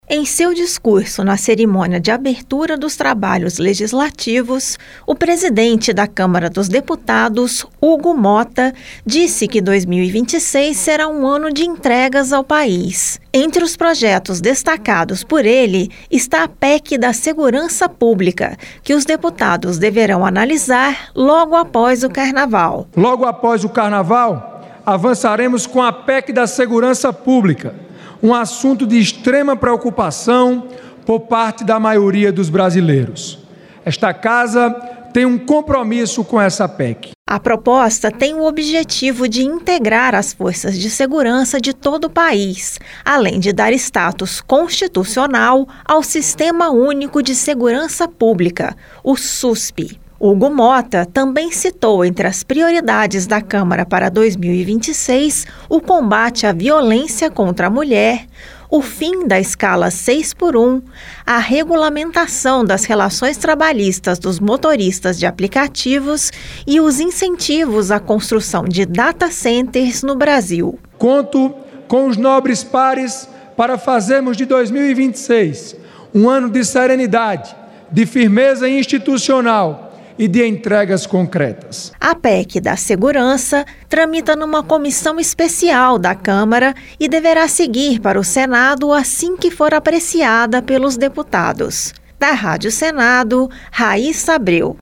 Em seu discurso na cerimônia de abertura dos trabalhos legislativos, o presidente da Câmara dos Deputados, Hugo Motta, disse que 2026 será um ano de entregas ao país. Entre os projetos destacados por Motta está a PEC da Segurança Pública, que os deputados deverão analisar logo após o Carnaval. Ele também citou o combate à violência contra a mulher e o fim da escala 6x1.